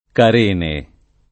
[ kar % ne ]